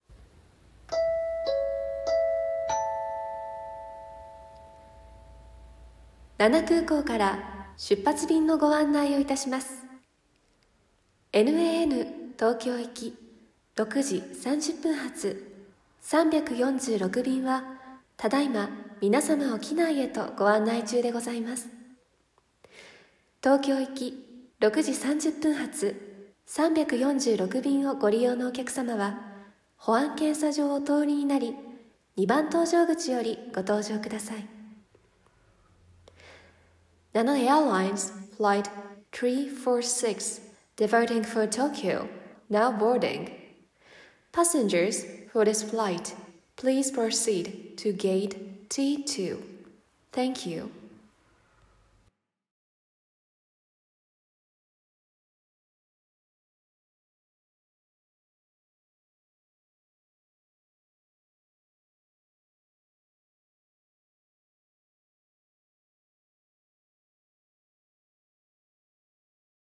空港アナウンス(ピンポンパンポン付き)